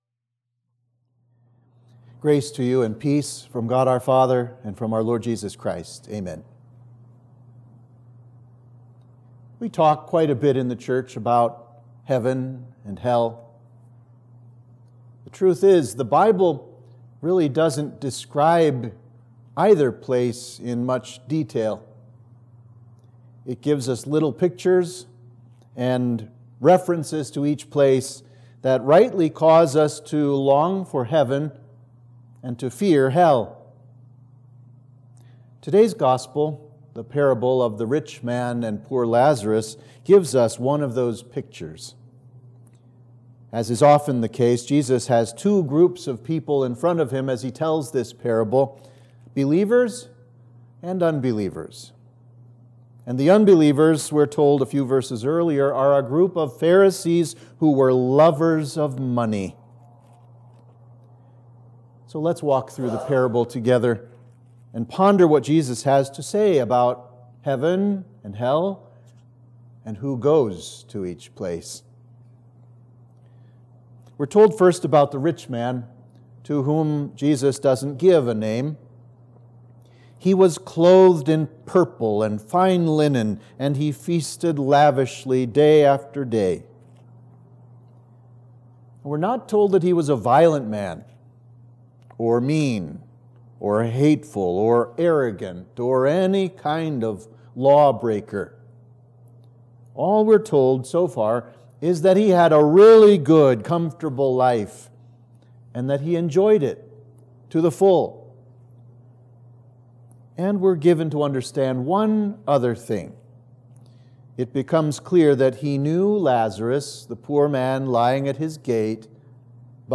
Sermon for Trinity 1